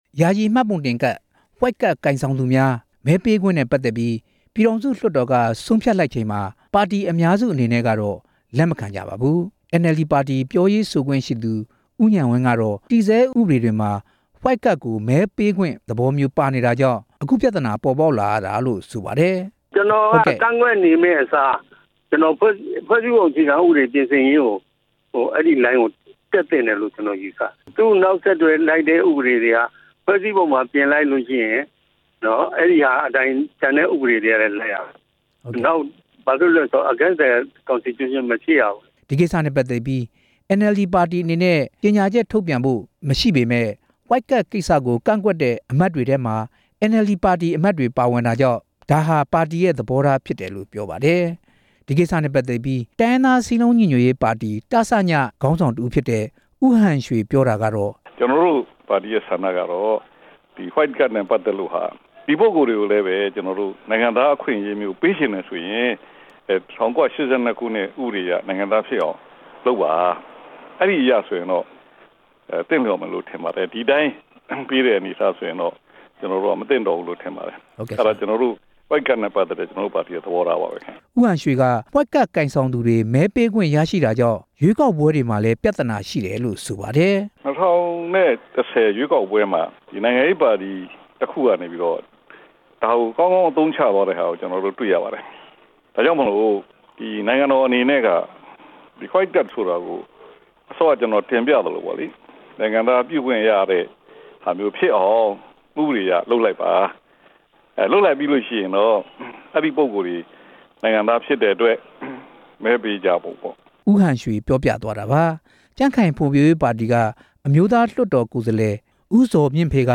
နိုင်ငံရေးပါတီအသီးအသီးရဲ့ ရပ်တည်ချက်တွေနဲ့ပတ်သက်လို့ တင်ပြချက်